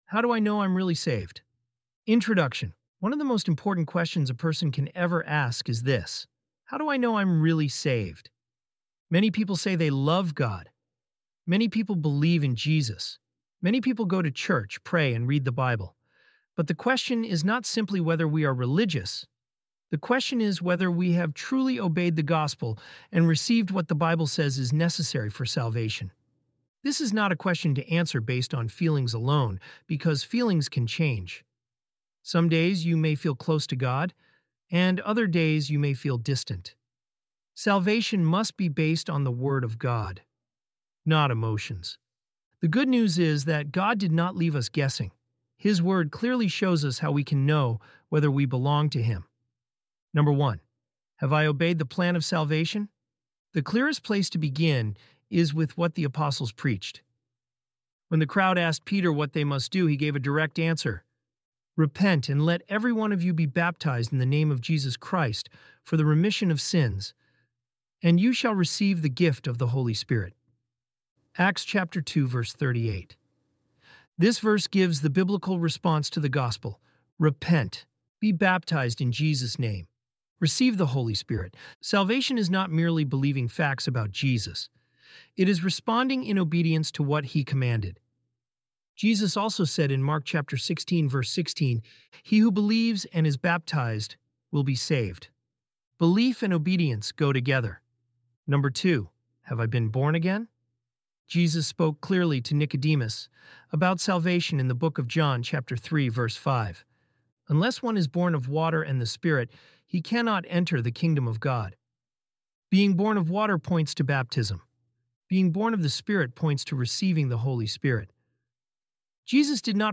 ElevenLabs_body-1.mp3